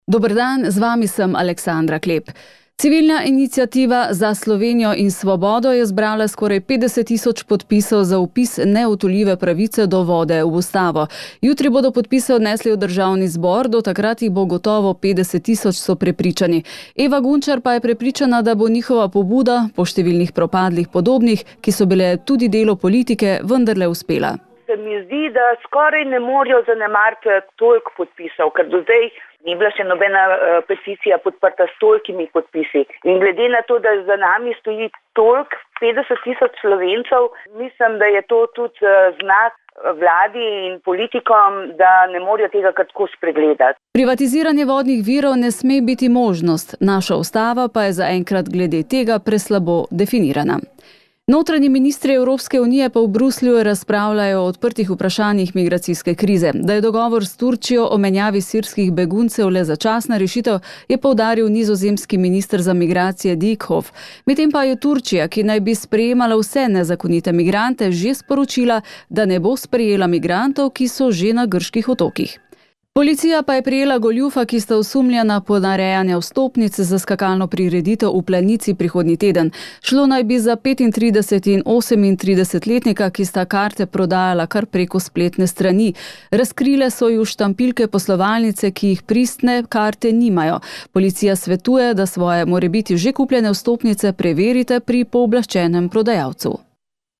Posnetek novic, v katerih so poročali o peticiji.